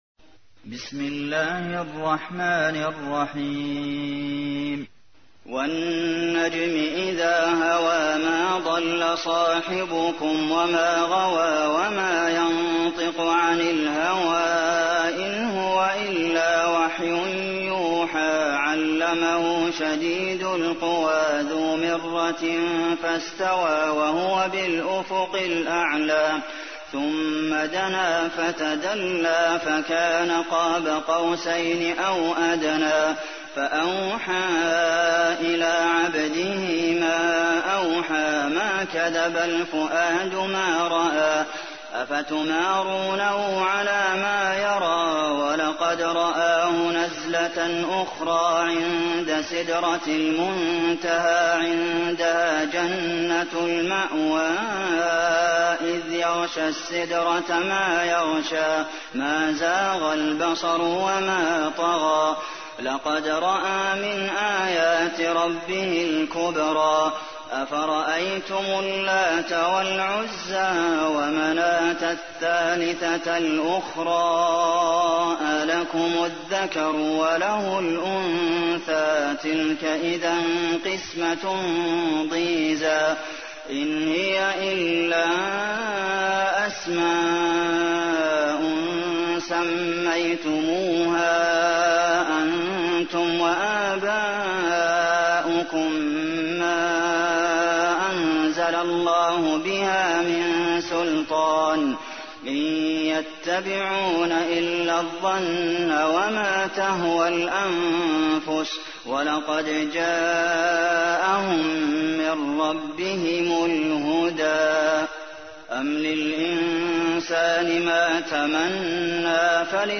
تحميل : 53. سورة النجم / القارئ عبد المحسن قاسم / القرآن الكريم / موقع يا حسين